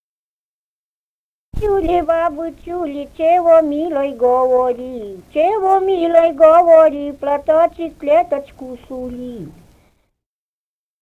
«Бабы чули, бабы чули» (частушки).